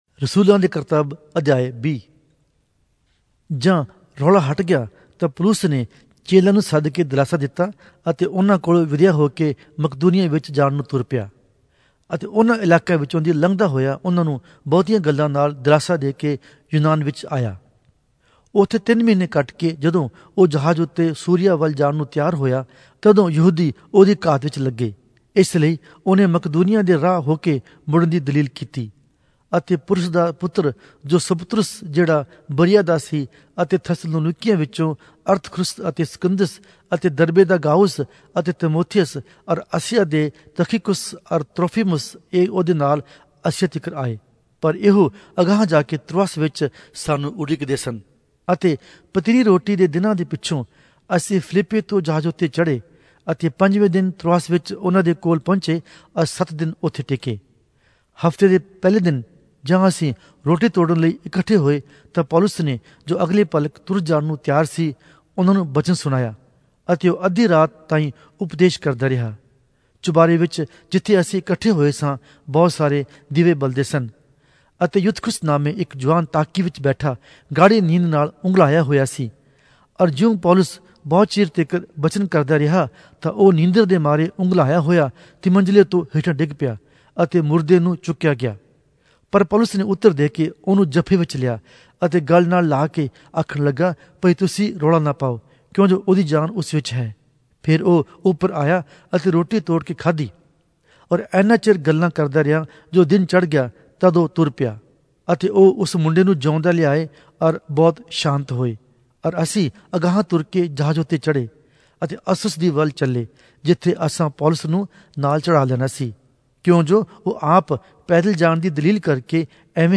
Punjabi Audio Bible - Acts 2 in Gntbrp bible version